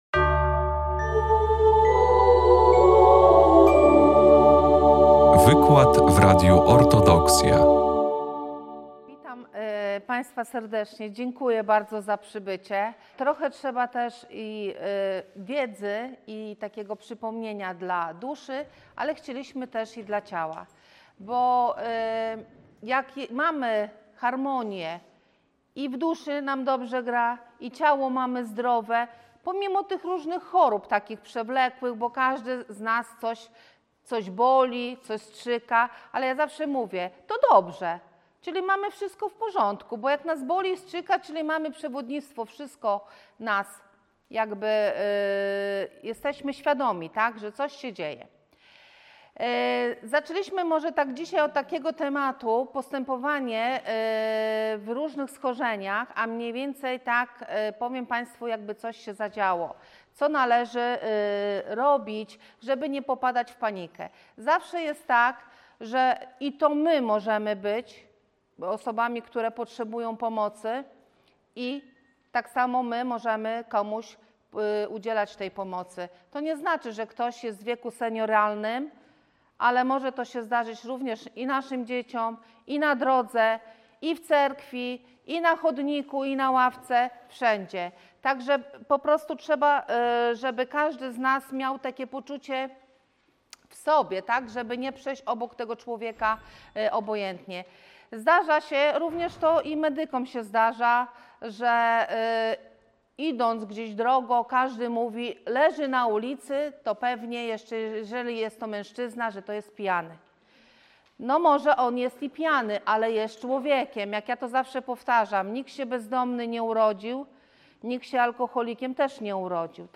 Wykład
wygłoszony 29.12.2025 w Centrum Kultury Prawosławnej w Białymstoku podczas Wszechnicy Kultury Prawosławnej.